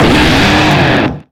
Cri de Roitiflam dans Pokémon X et Y.